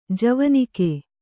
♪ javanike